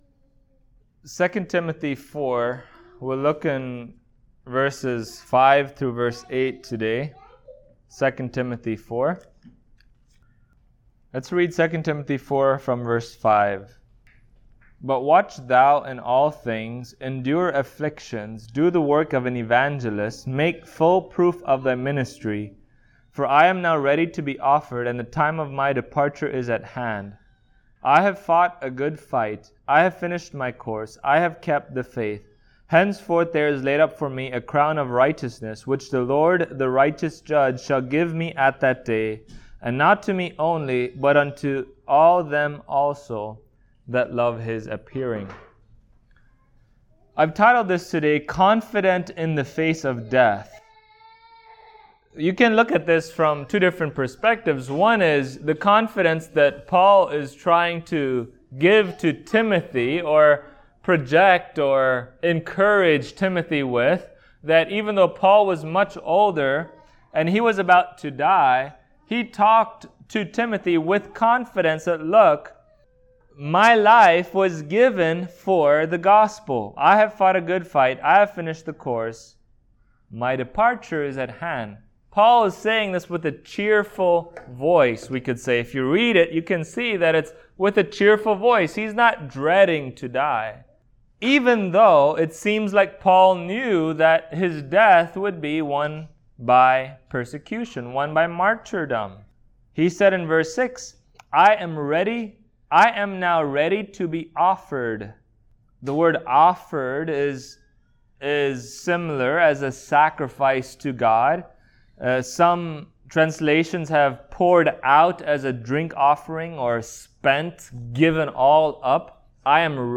Passage: 2 Timothy 4:5-8 Service Type: Sunday Morning